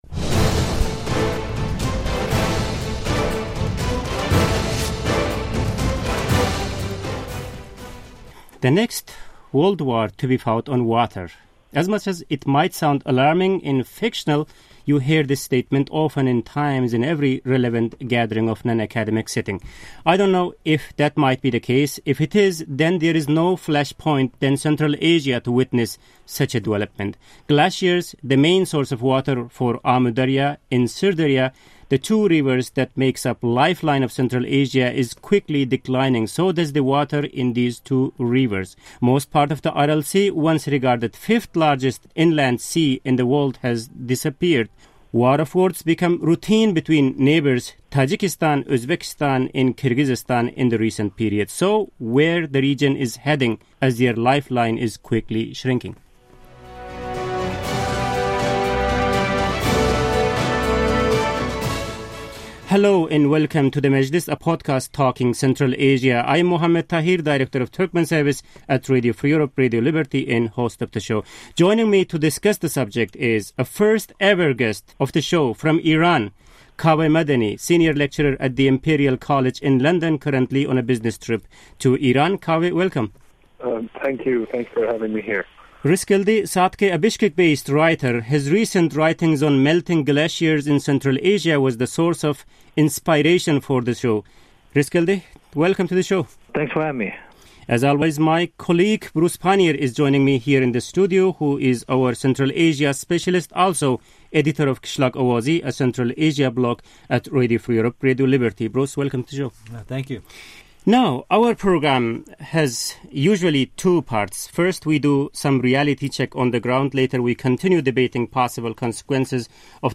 RFE/RL's Turkmen Service, known locally as Azatlyk, assembled a panel discussion (majlis) to review the water situation in Central Asia and neighboring states, examine regional water-supply forecasts for 35, 50, or 100 years from now, and consider whether water could be cause for conflict there.
Roundtable: The Receding Waters Of Central Asia